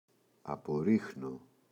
απορρίχνω [apo’rixno]: αποβάλλω (για ζώα).
απορρίχνω.mp3